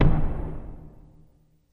Hammer Hits | Sneak On The Lot